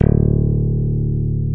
CHPMN STICK.wav